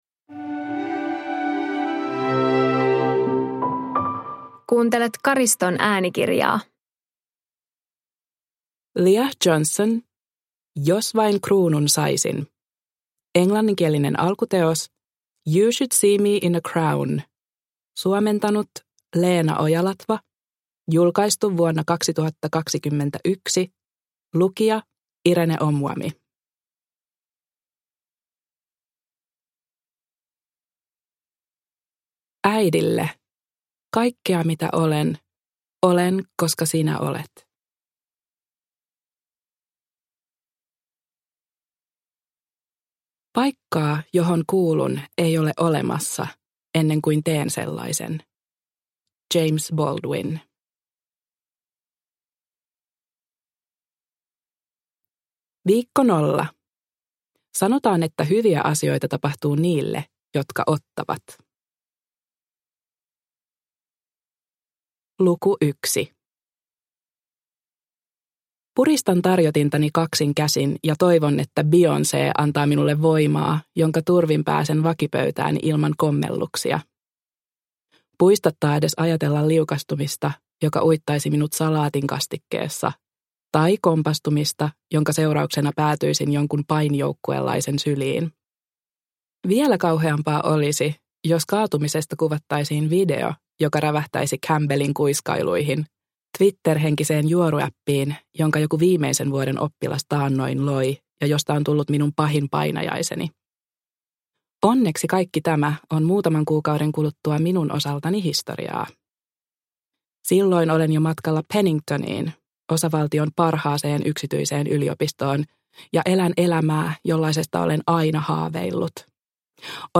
Jos vain kruunun saisin – Ljudbok – Laddas ner